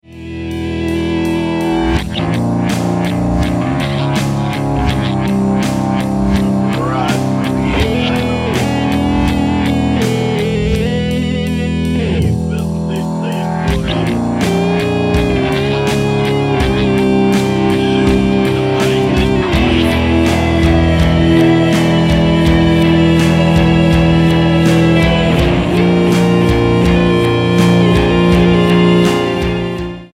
Dark rawk for doobious times.
voices, lead guitar